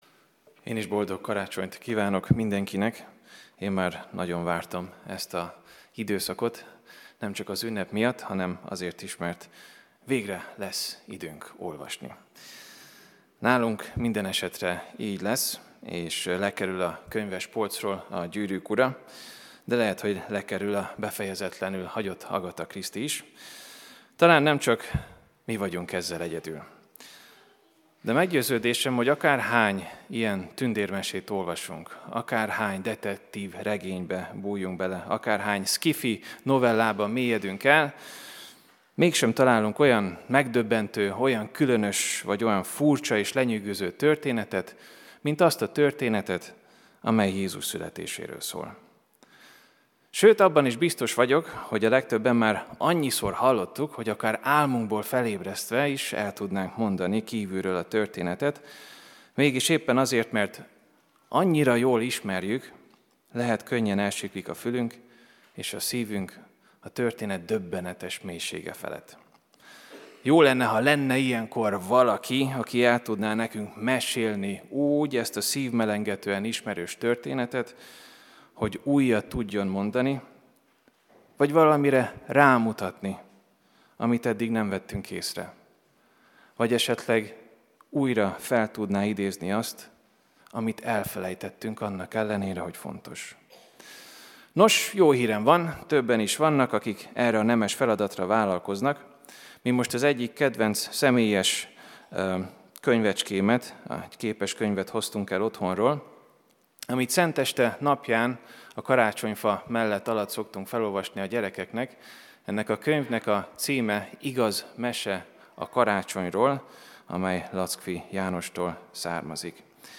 Szentesti áhítat
AZ IGEHIRDETÉS LETÖLTÉSE PDF FÁJLKÉNT AZ IGEHIRDETÉS MEGHALLGATÁSA